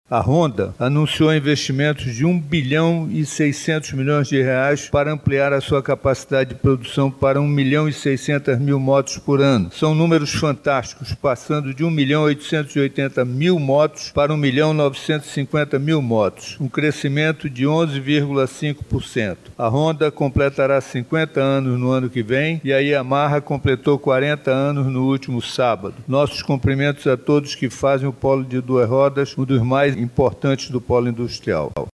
Ao final da reunião, o secretário de Estado de Desenvolvimento, Ciência, Tecnologia e Inovação, Serafim Corrêa, homenageou as duas maiores empresas do setror de Duas Rodas do PIM.